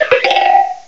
The cries from Chespin to Calyrex are now inserted as compressed cries